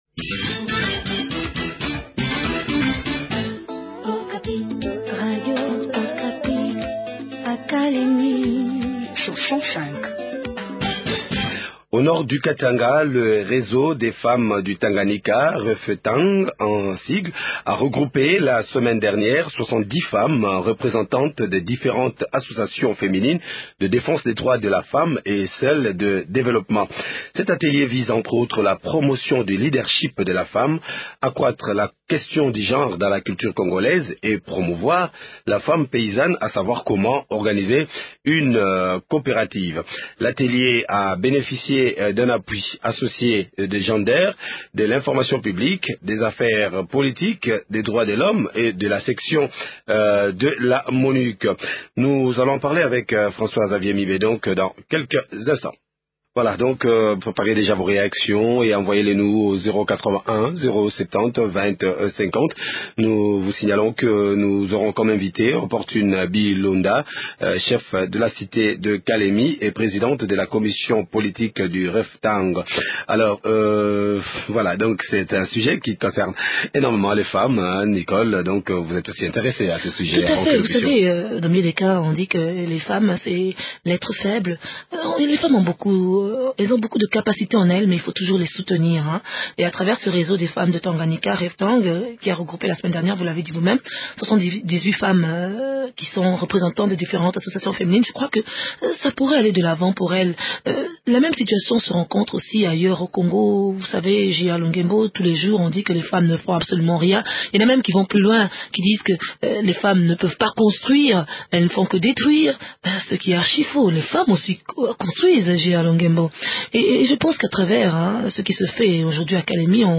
Le point dans cet entretien